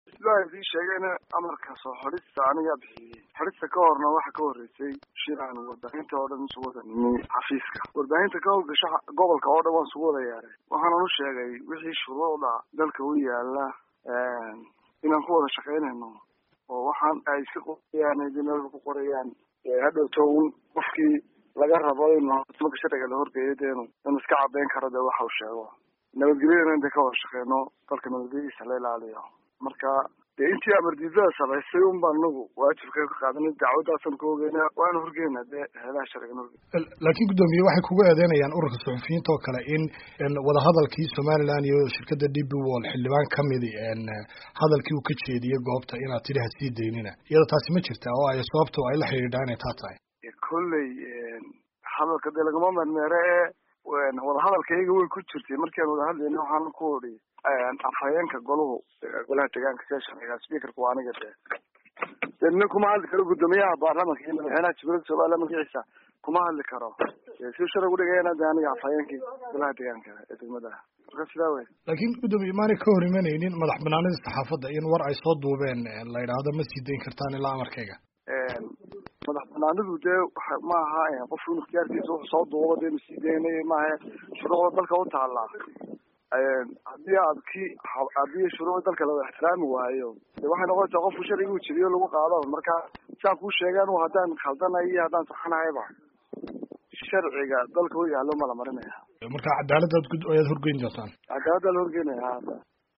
Wareysi: Duqa magaalada Berbera